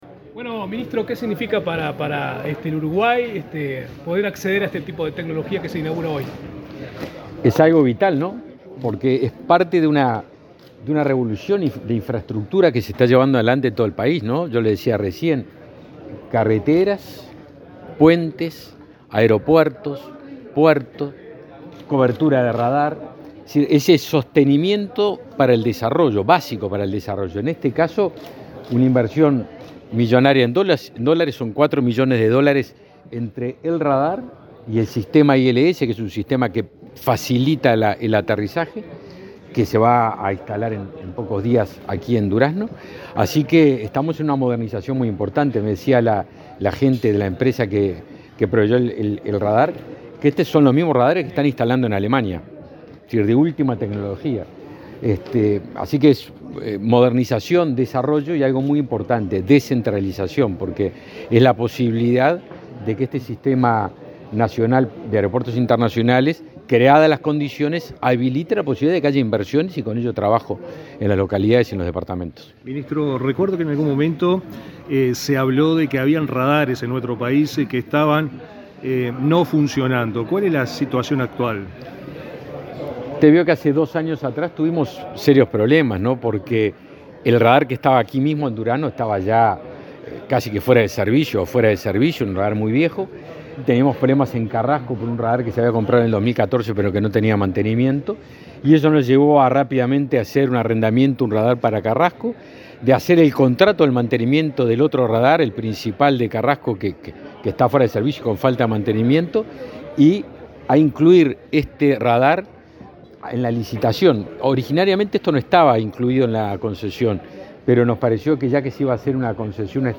Declaraciones del ministro de Defensa Nacional, Javier García
El titular de la cartera, Javier García, participó en el acto y luego dialogó con la prensa.